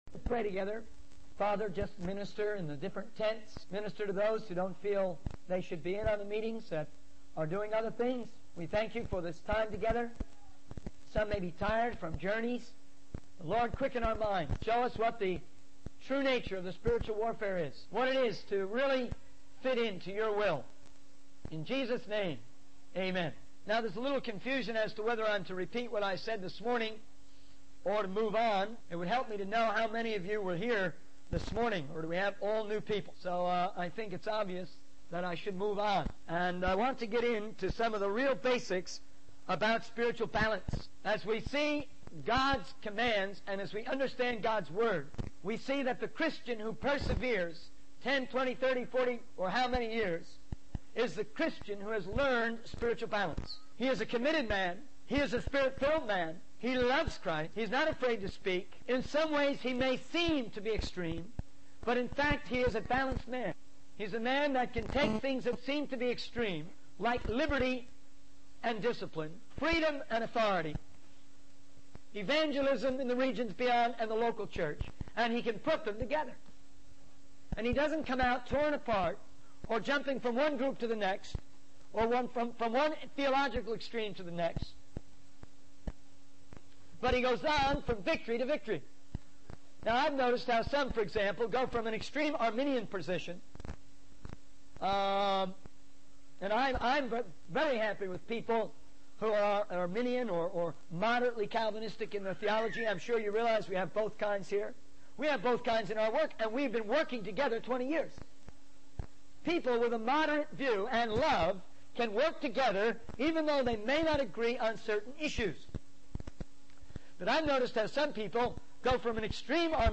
In this sermon, the speaker discusses the importance of understanding how God works through people, rather than relying solely on one individual's ideas. He emphasizes the need for a fellowship of people who love and support one another. The speaker also addresses the challenges and discouragements that can arise when doing God's work, particularly in evangelizing the Muslim world.